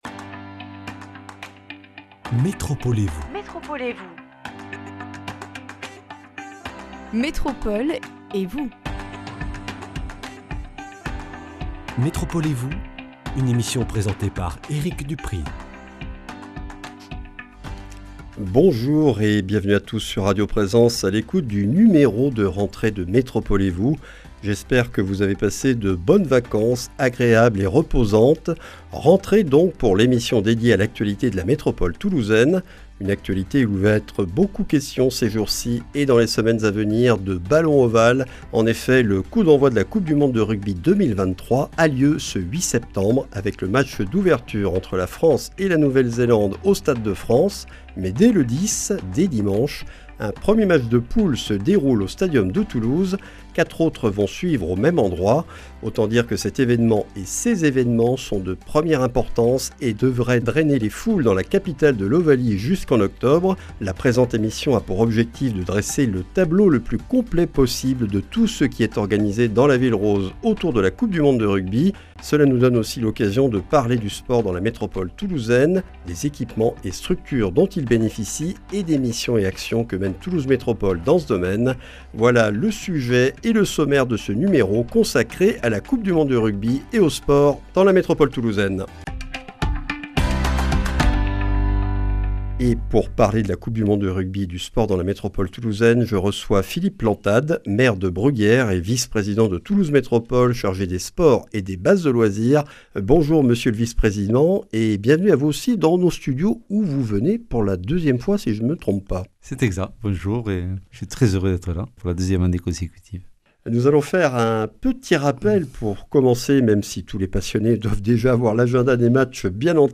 Philippe Plantade, maire de Bruguières et vice-président de Toulouse Métropole chargé des sports et des bases de loisirs, est l’invité de notre numéro de rentrée. Alors que commence la coupe du monde de rugby (avec cinq matchs de poule à Toulouse), nous revenons avec lui sur les manifestations organisées dans la métropole toulousaine autour de l’événement (Village Rugby à la Prairie des Filtres) et sur les actions de Toulouse Métropole dans le domaine des sports et loisirs.